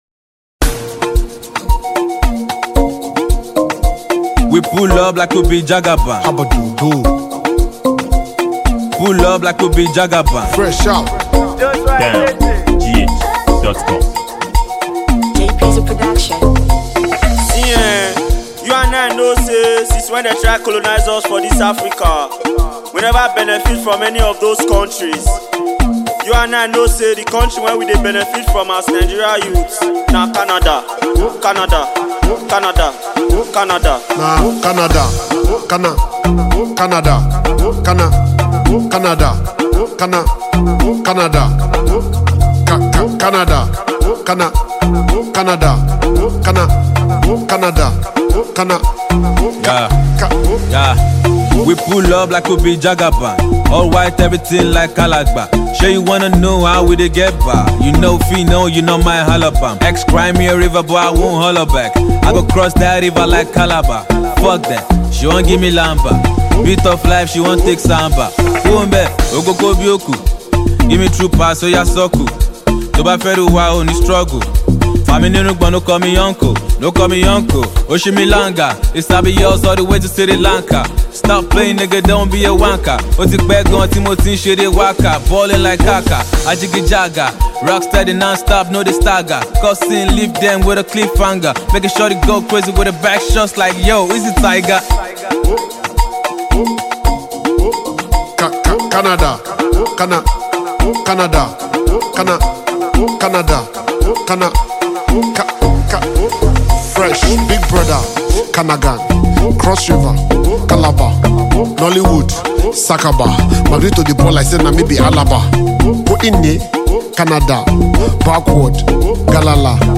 afrobeat song genre